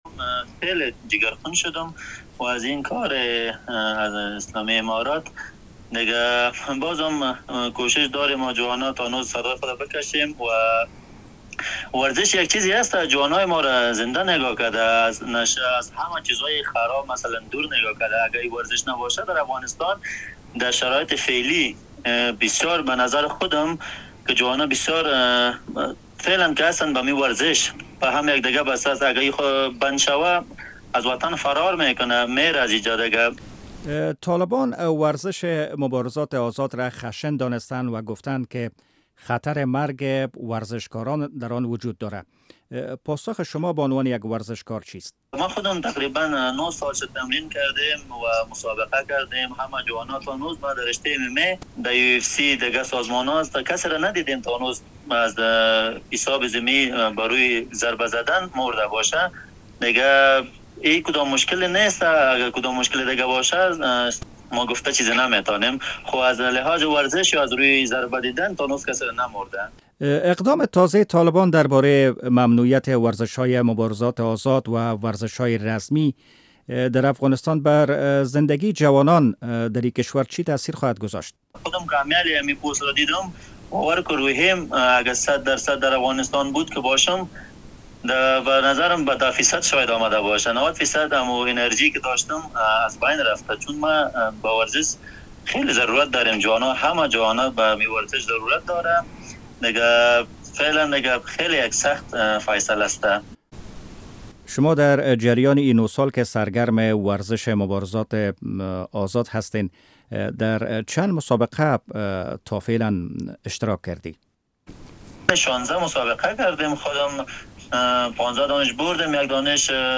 مصاحبه‌های ورزشی